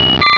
Cri de Chenipan dans Pokémon Rubis et Saphir.